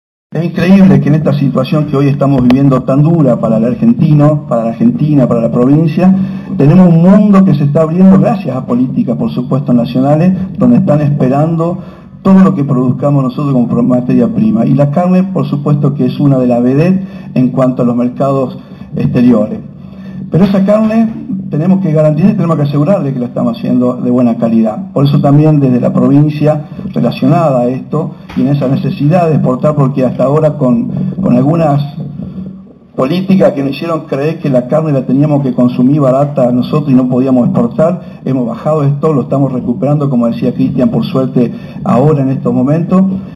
El Secretario de Lechería, Ganadería y Recursos Naturales, Roberto Tión, sorprendió a propios y extraños con un discurso apoyando las políticas nacionales siendo que el gobierno provincial tiene habitualmente fuertes críticas hacia el destino al que lleva Cambiemos al país.
“Tenemos un mundo que se está abriendo gracias a políticas nacionales donde están esperando todo lo que produzcamos nosotros como materia prima y la carne es una de las vedettes en los mercados mundiales”, comenzó diciendo en la inauguración del Frigorífico de Malabrigo y donde fue el único representante del gobierno provincial.
Palabras de Roberto Tión: